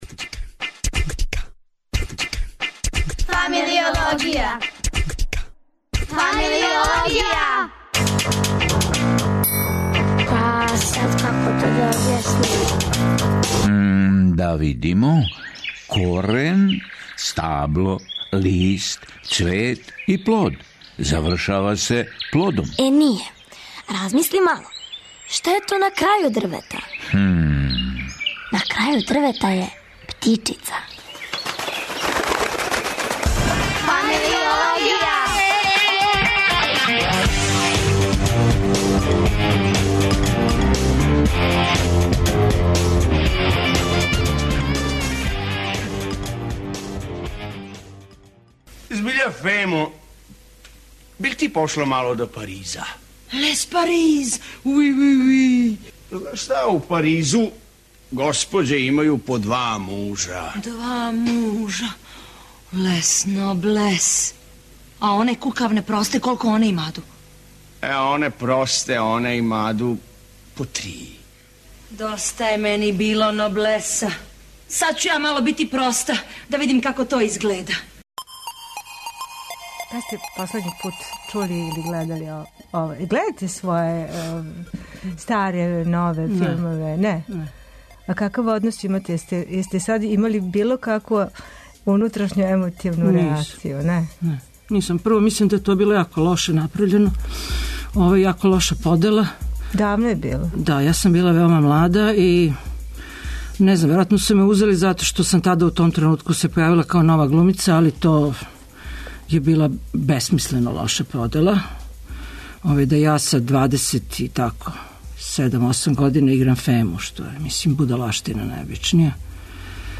Један од његових најчешће помињаних ликова, Фему из Покондирене тикве у истоименом филму из 1986. године, тумачила је гошћа у студију, глумица, редитељка и професорка, Мирјана Карановић.